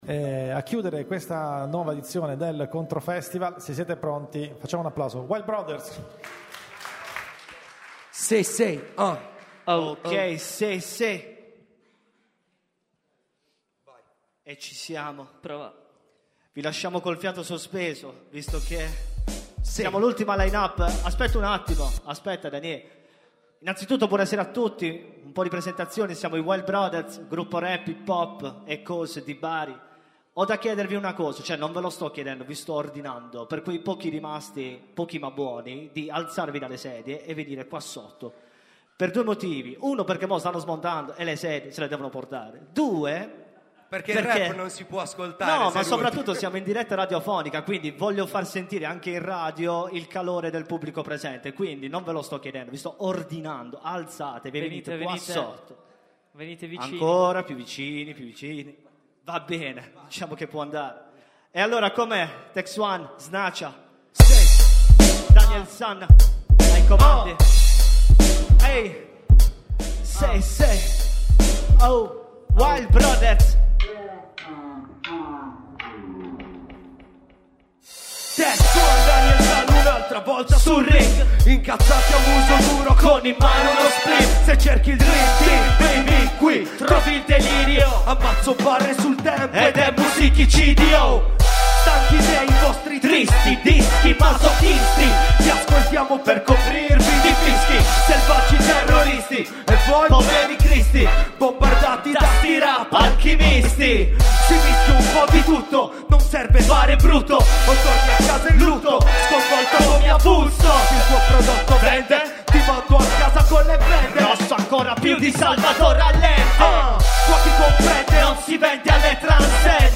collettivo rap